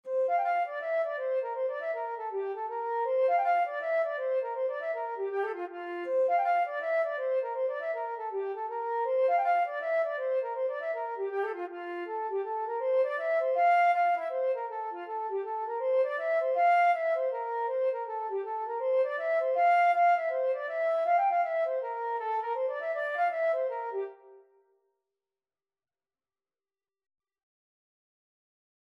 F major (Sounding Pitch) (View more F major Music for Flute )
4/4 (View more 4/4 Music)
F5-G6
Flute  (View more Intermediate Flute Music)
Traditional (View more Traditional Flute Music)